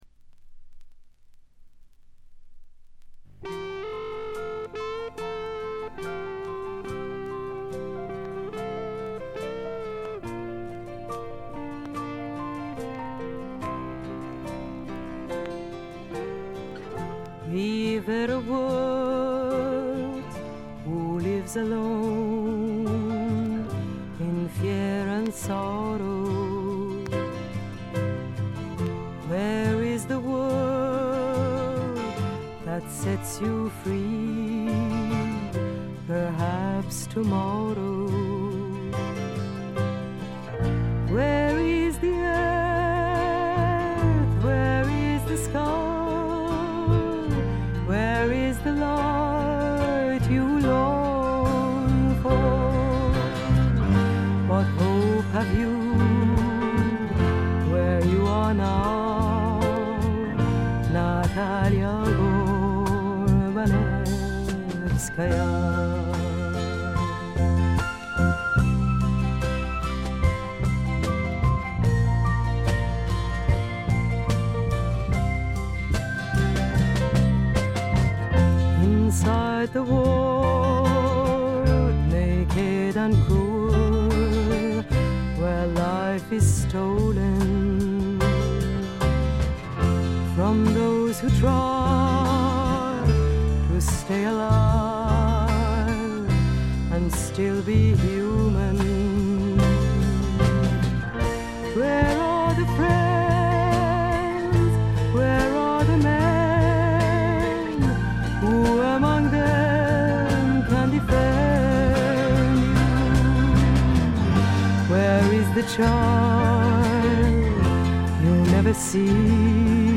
ところどころでチリプチ。散発的なプツ音が少々。
彼女の魅力はなんと言ってもしっとりとした情感があふれるアルト・ヴォイスです。
試聴曲は現品からの取り込み音源です。
vocals
piano, electric piano, organ, percussion
bass, percussion
guitar, 12-string guitar, percussion
drums, percussion